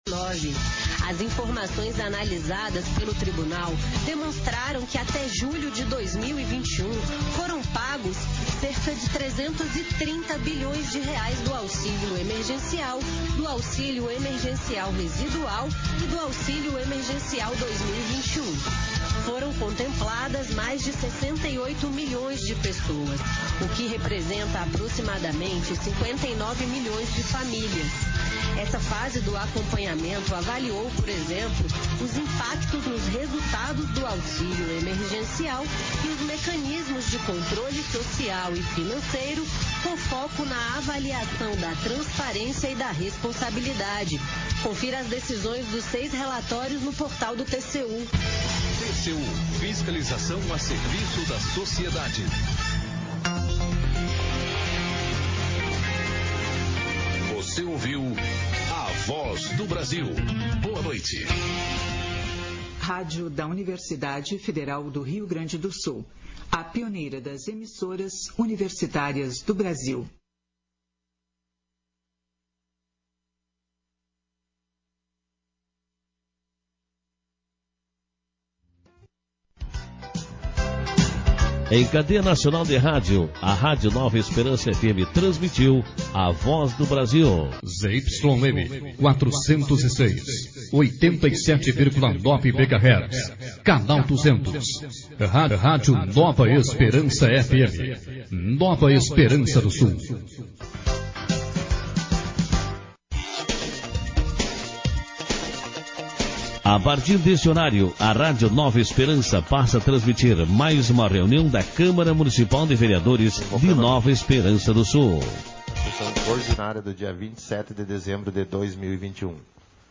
Sessão Ordinária 47/2021